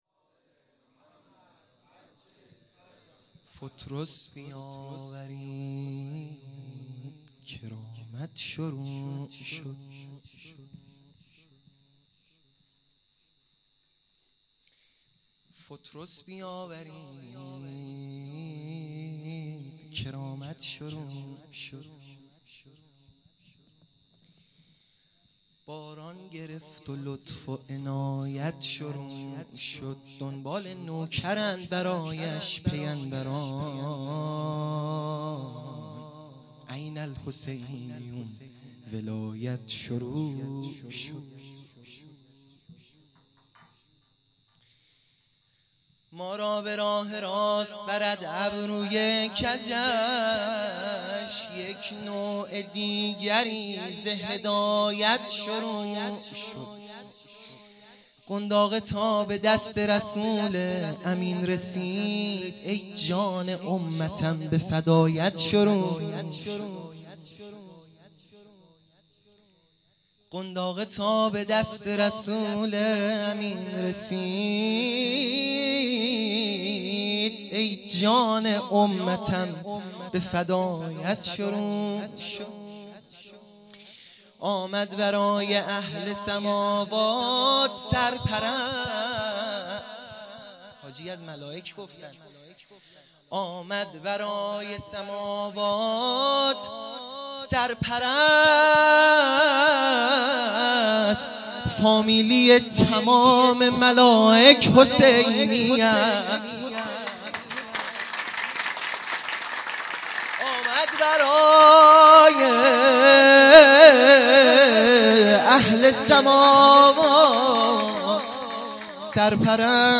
مدح ا فطرس بیاورید
عیدانه سرداران کربلا | شب اول